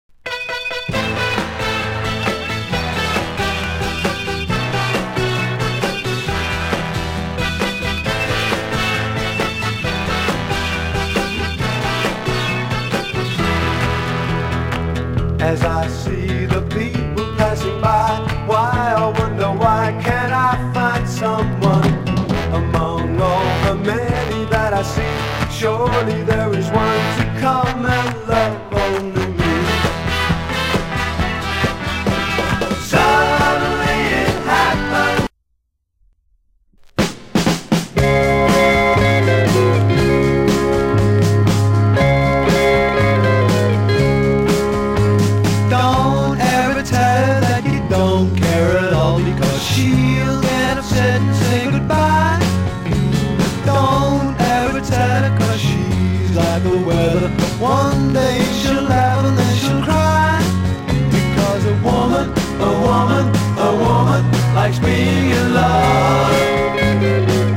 まるで70年代アイドル歌謡の様なイントロにエレキシタールが心地良く絡むソフトロック。
(税込￥1650)   SOFT ROCK